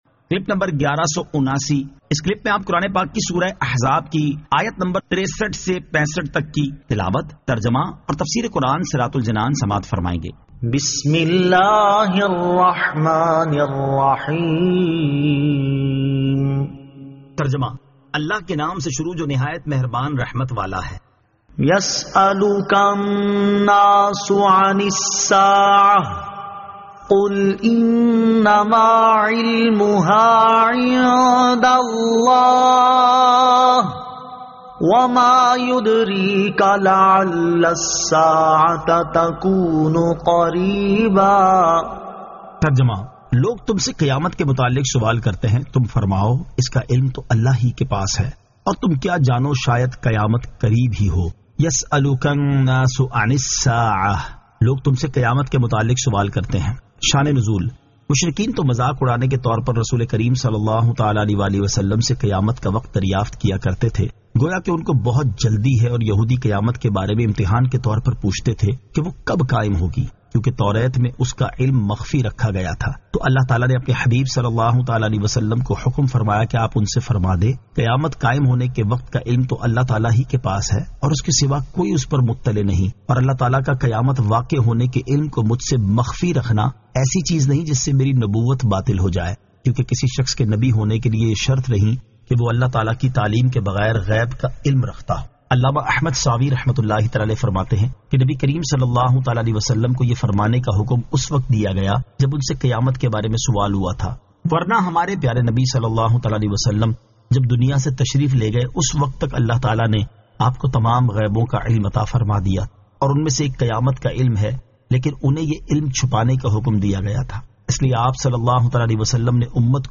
Surah Al-Ahzab 63 To 65 Tilawat , Tarjama , Tafseer